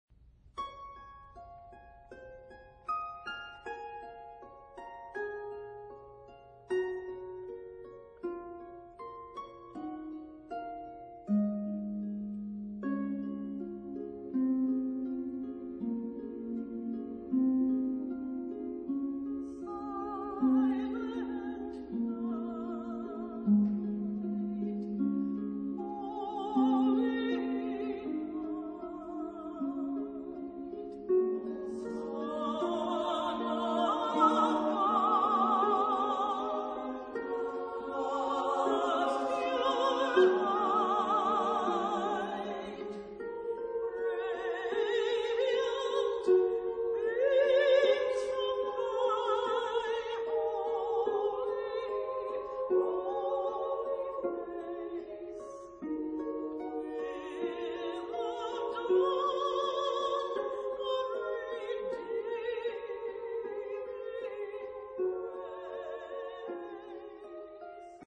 Genre-Style-Form: Carol ; Arrangement
Type of Choir: SATB divisi  (4 mixed voices )
Soloist(s): Alto (1)  (1 soloist(s))
Instrumentation: harp  (1 instrumental part(s))
Tonality: D flat major